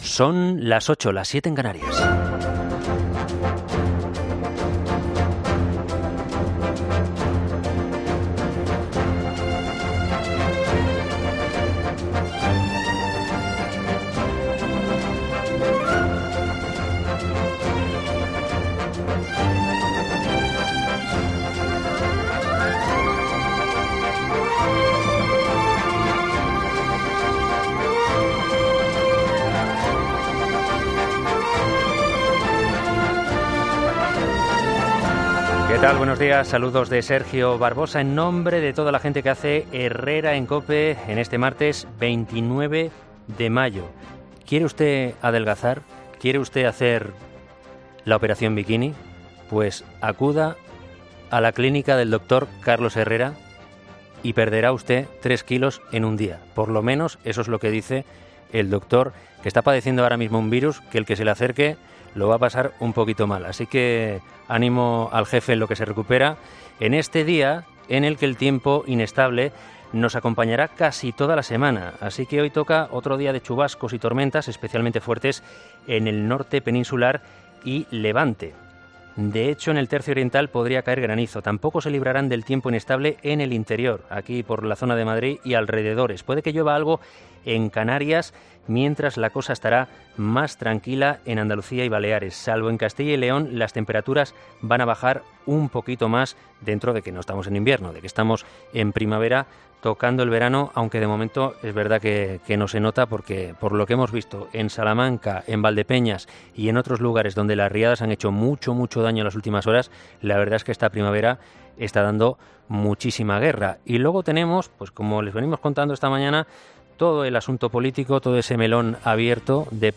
Monólogo de las 8 de Herrera
Editorial de 'Herrera en Cope'